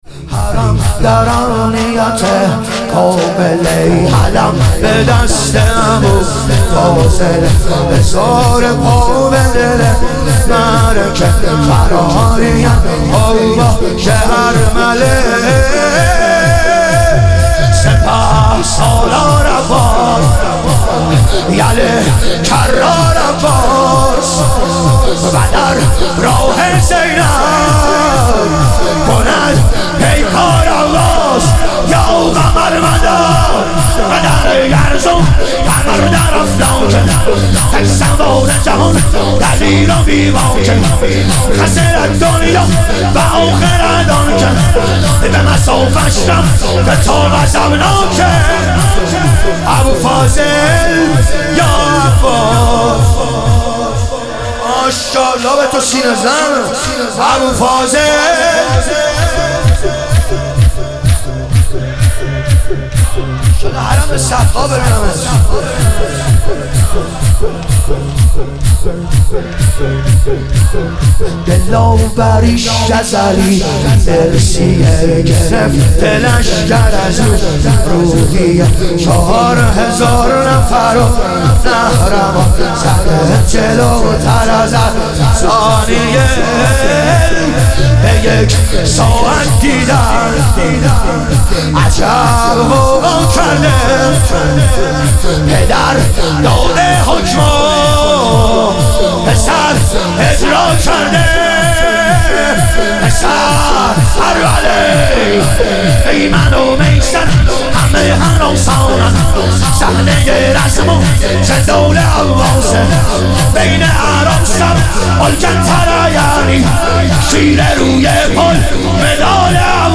شور
شب شهادت امام حسن مجتبی علیه السلام